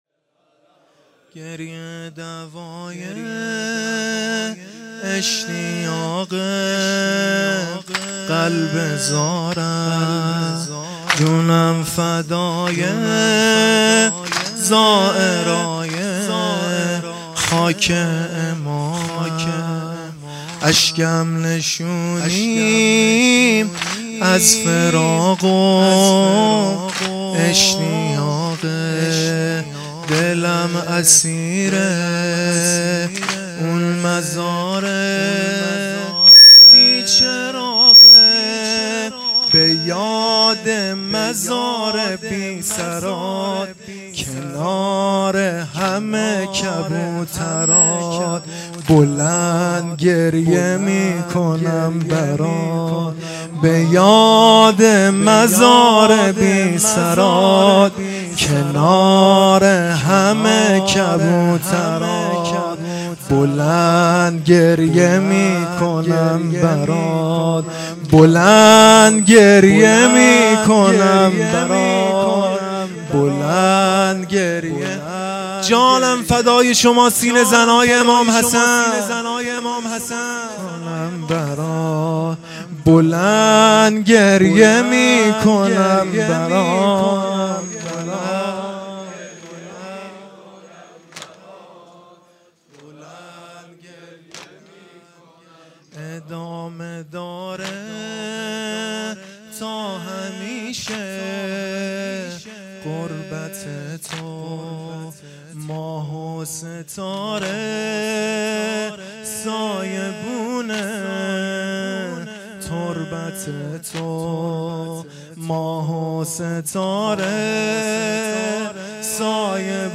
محرم 1440